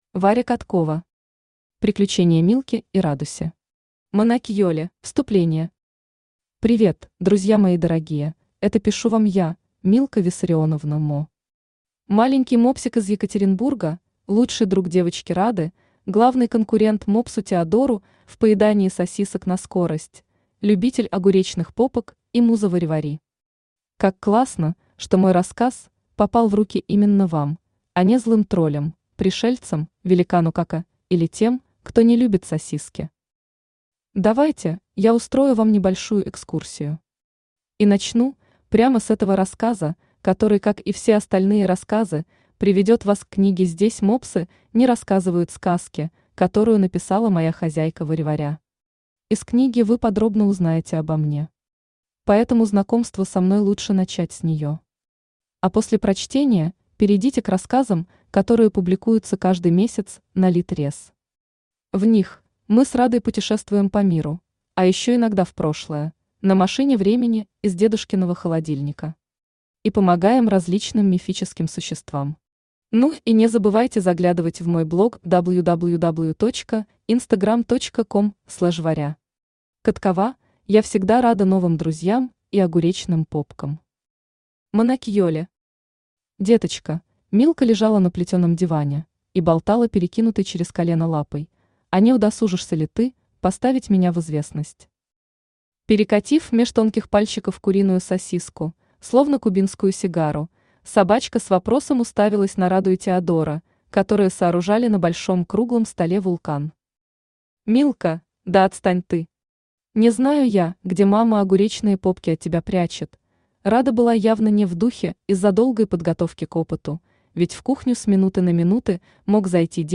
Аудиокнига Приключения Милки и Радуси. Монакьелли | Библиотека аудиокниг
Монакьелли Автор Варя Каткова Читает аудиокнигу Авточтец ЛитРес.